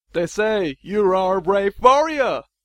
Englische Sprecher (m)
English: scratchy , shifty , Exotic